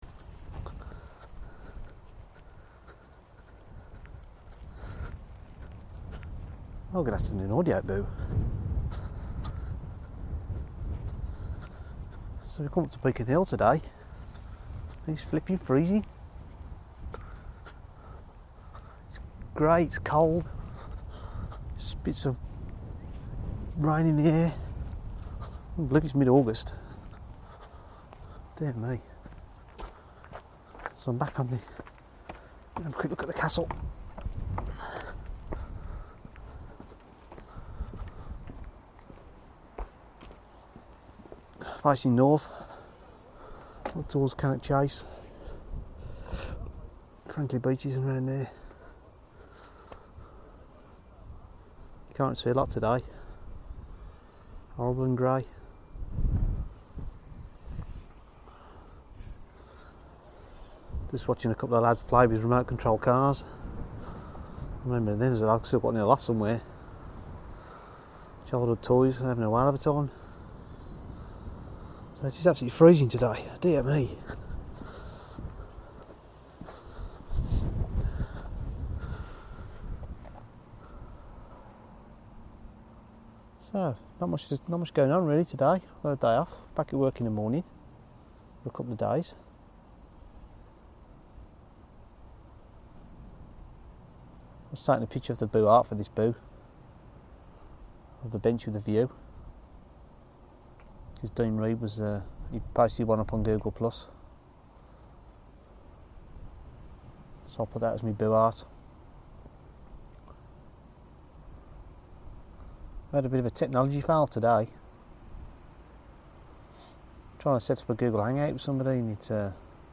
A cold boo from Beacon Hill today, grey and windy. Sorry its a bit quiet, new setting on the audio recorder.